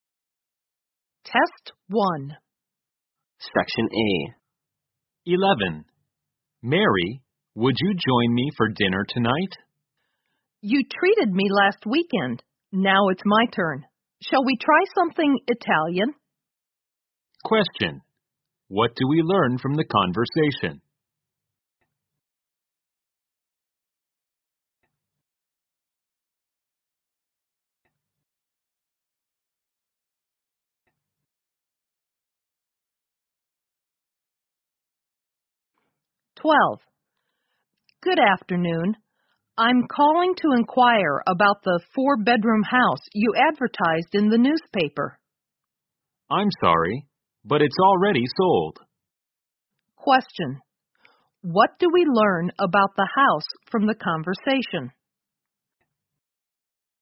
在线英语听力室001的听力文件下载,英语四级听力-短对话-在线英语听力室